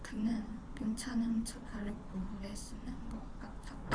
tts-fastspeech2-mydata like 0 Runtime error